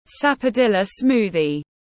Sinh tố hồng xiêm tiếng anh gọi là sapodilla smoothie, phiên âm tiếng anh đọc là /sæpou’dilə ˈsmuːðiz/
Sapodilla smoothie /sæpou’dilə ˈsmuːðiz/